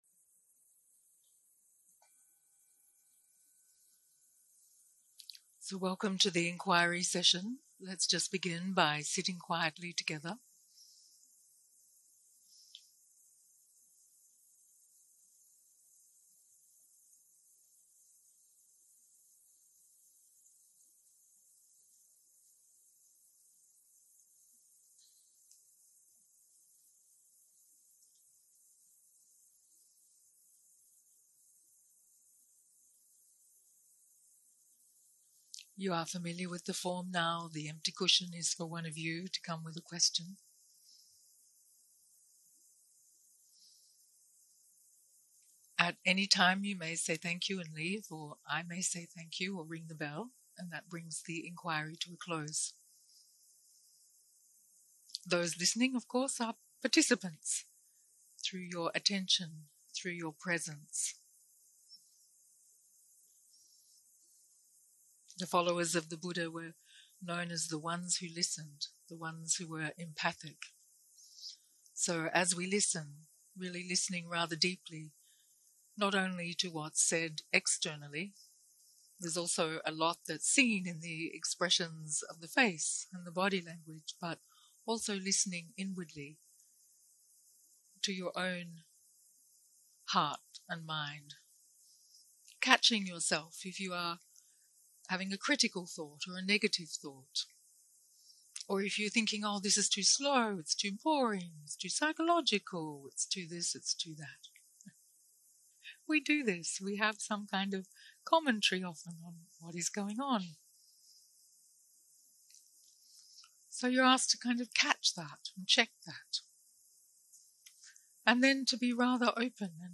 Dharma type: Inquiry